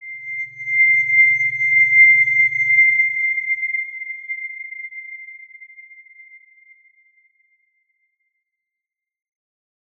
X_Windwistle-C6-ff.wav